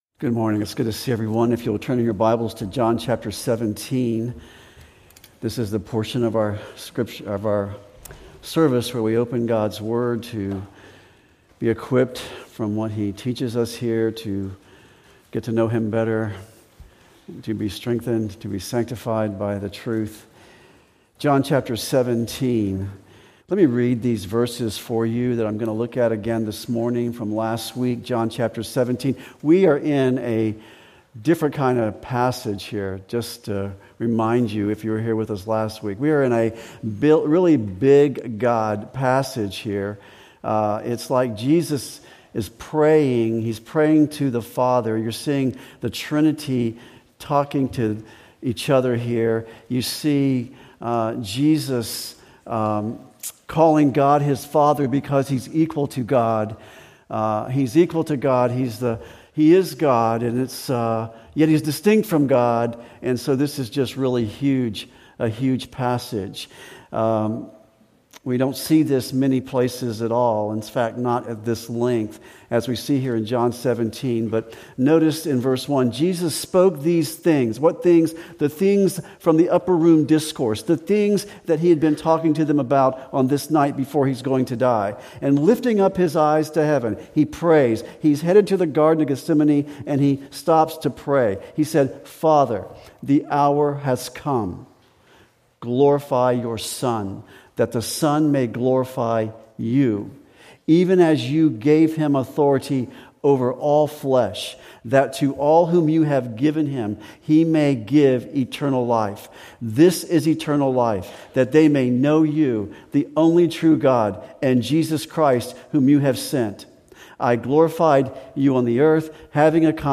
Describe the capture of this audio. Sermons preached at Grace Church of Tallahassee, where the Word of God has supreme authority in all matters of faith and conduct and is sufficient for all matters of life and godliness.